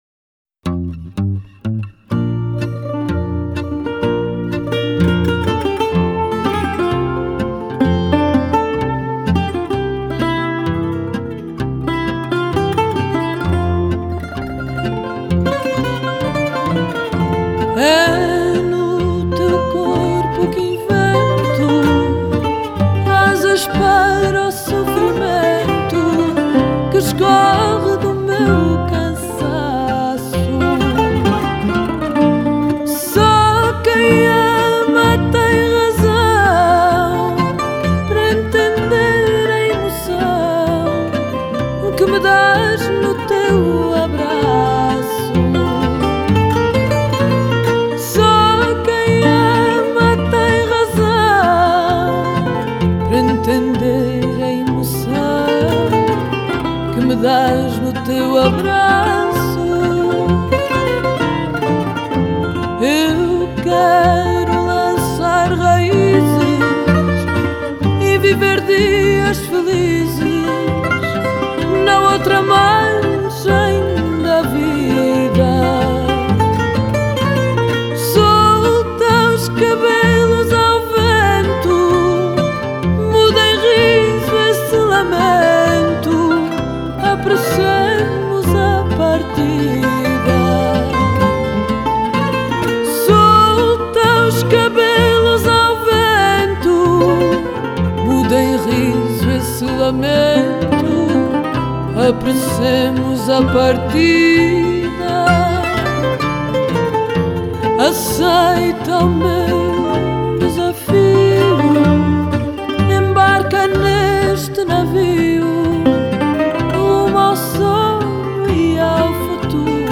Genre: World, Fado